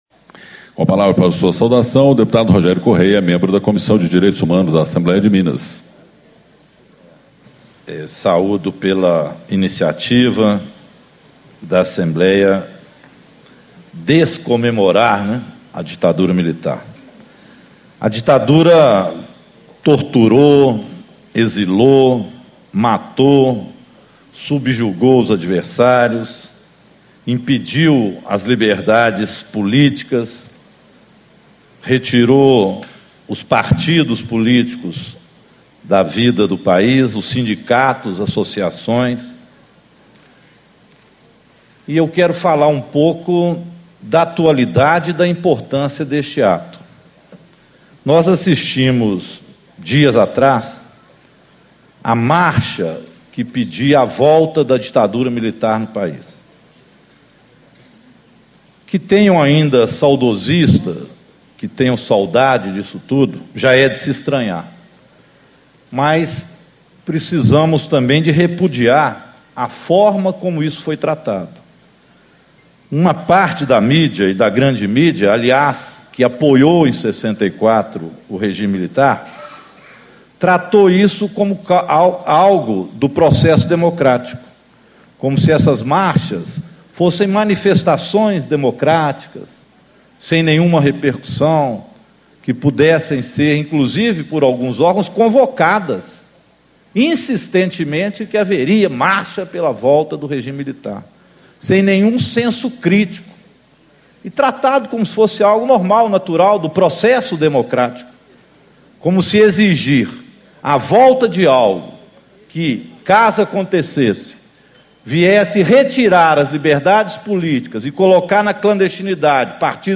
Abertura - Deputado Rogério Correia, PT
Discursos e Palestras